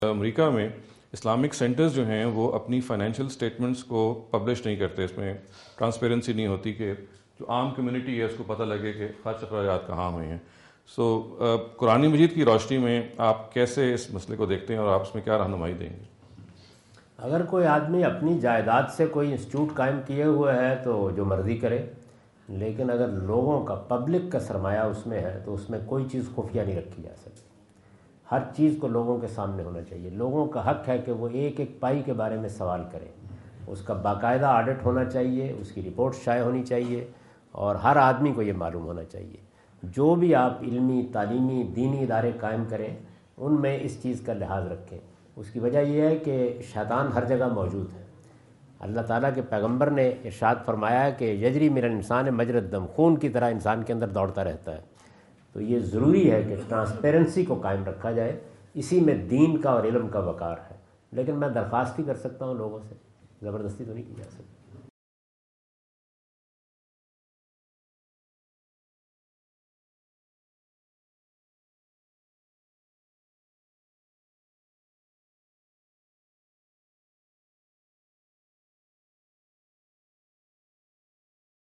Category: English Subtitled / Questions_Answers /
Javed Ahmad Ghamidi answer the question about "Revealing Financial Details of Public-Funded Islamic Centers" asked at Corona (Los Angeles) on October 22,2017.
جاوید احمد غامدی اپنے دورہ امریکہ 2017 کے دوران کورونا (لاس اینجلس) میں "خیراتی اداروں کے مالی معاملات کی تفصیلات ظاہر کرنا" سے متعلق ایک سوال کا جواب دے رہے ہیں۔